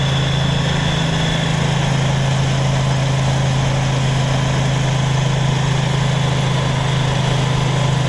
西非 " 交通密集的林荫道 摩托车、轻便摩托车、卡车2 达喀尔，塞内加尔，非洲
描述：交通密集的大道摩托车，轻便摩托车，卡车达喀尔，塞内加尔，非洲